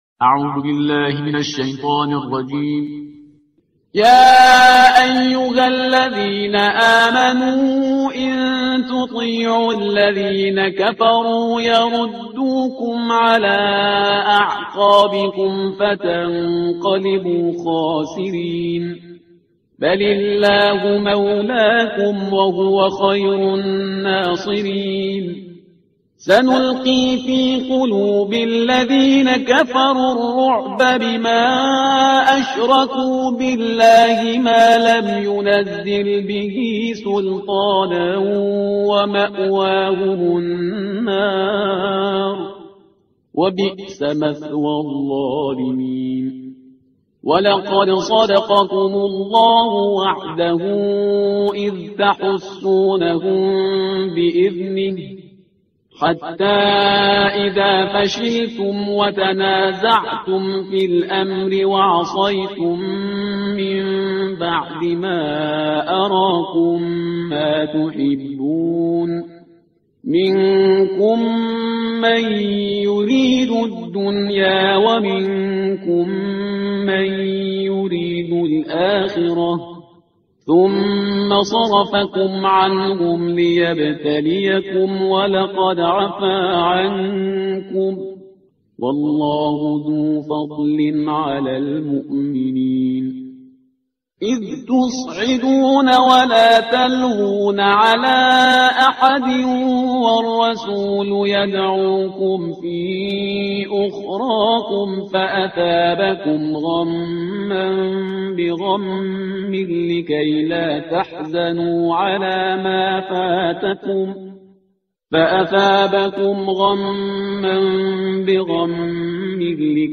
ترتیل صفحه 69 قرآن با صدای شهریار پرهیزگار